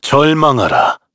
Shakmeh-Vox_Skill1_kr_b.wav